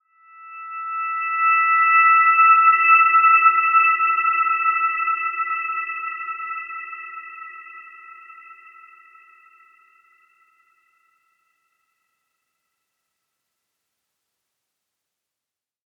Dreamy-Fifths-E6-mf.wav